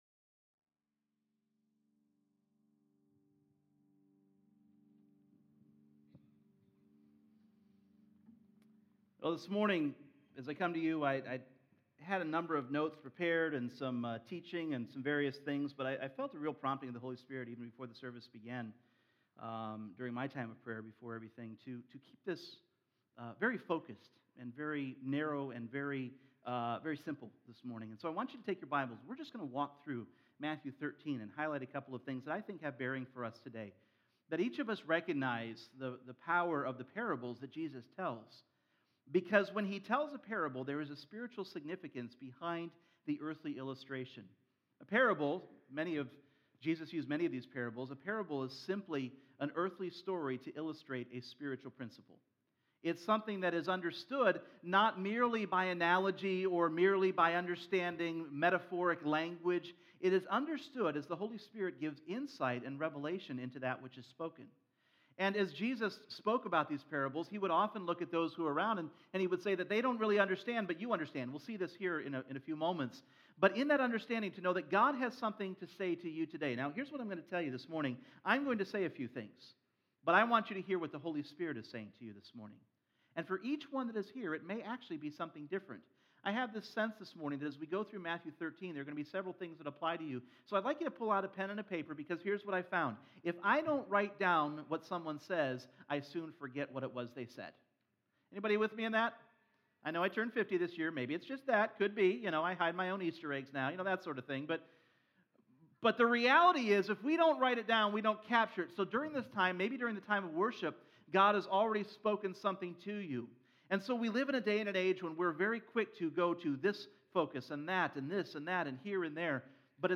Individual Messages Service Type: Sunday Morning We all want faith